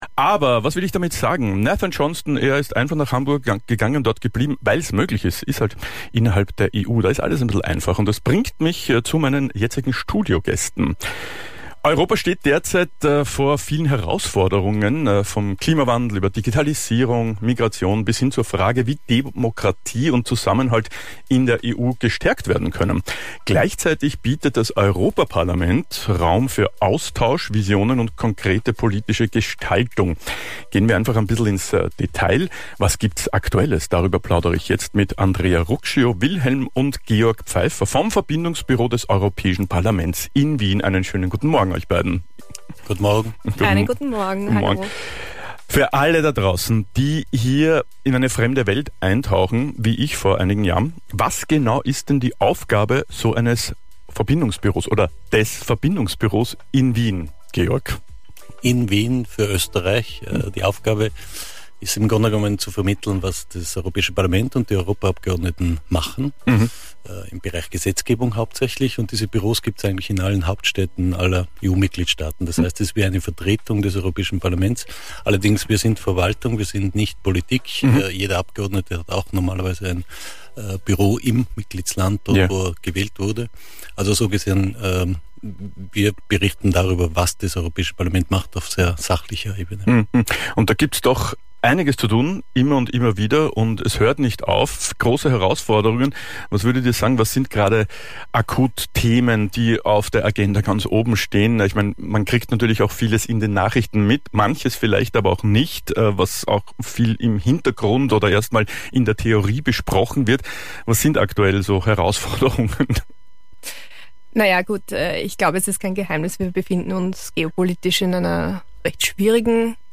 Superfly Interviews | Aktuelles in Europa